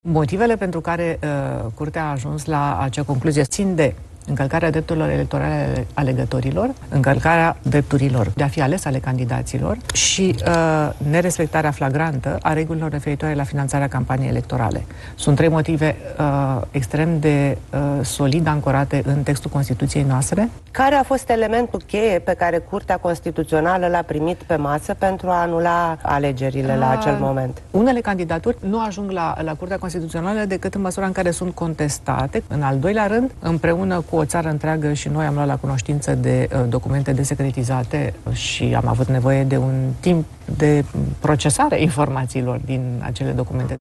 Curtea Constituțională a avut trei motive esențiale pentru a lua această decizie fără precedent în România, a declarat președinta CCR la televiziunea publică. Simina Tănăsescu a explicat de ce decizia de anulare a venit după ce, inițial, CCR a validat primul tur al alegerilor.
Președinta CCR, Simina Tănăsescu: „Sunt trei motive extrem de solid ancorate în textul Constituției noastre”